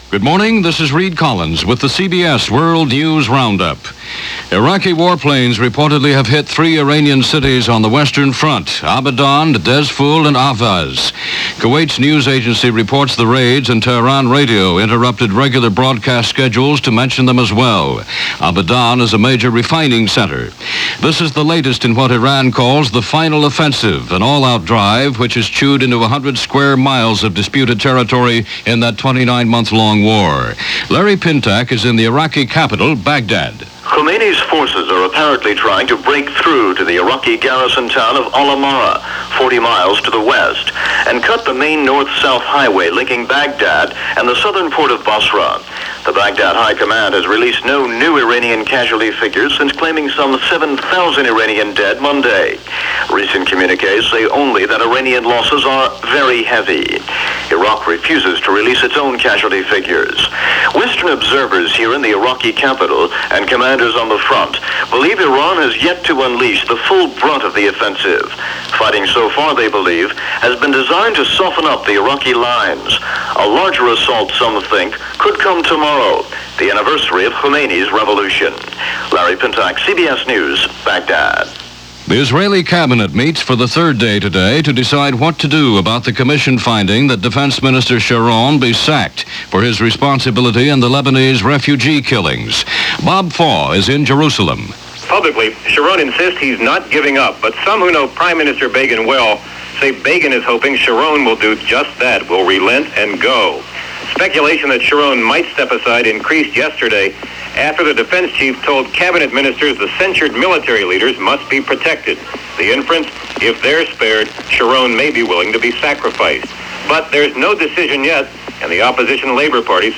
News for this day in 1983 from CBS Radio.